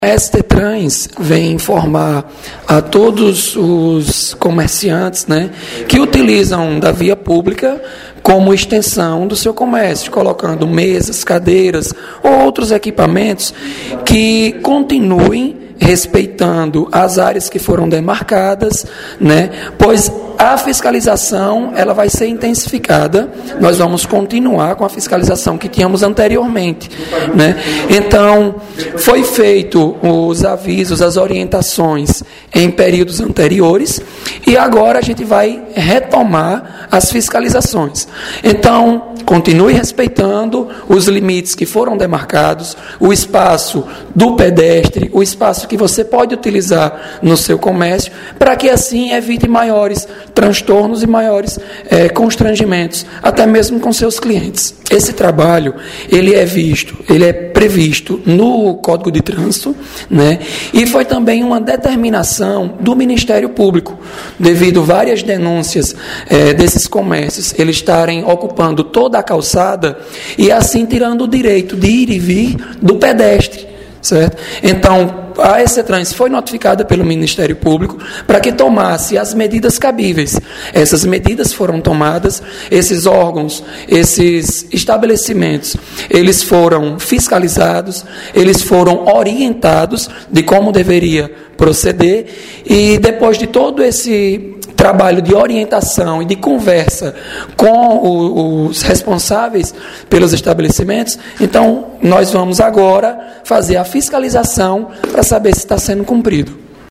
Fala